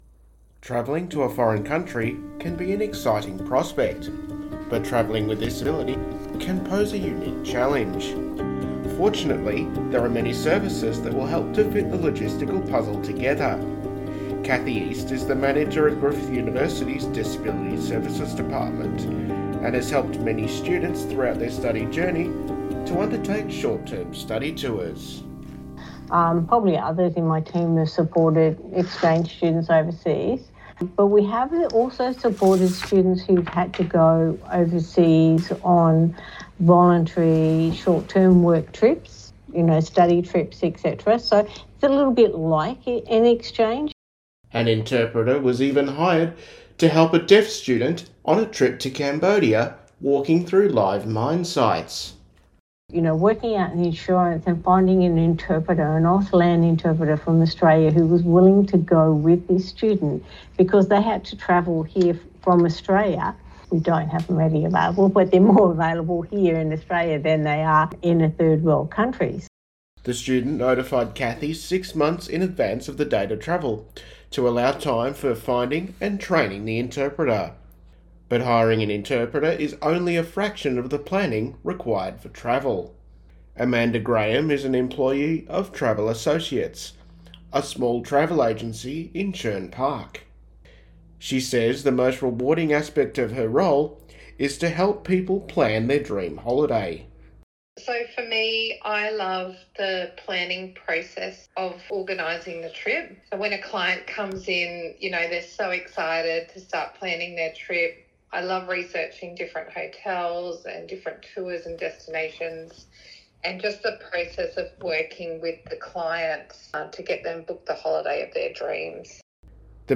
broadcast-package-final.mp3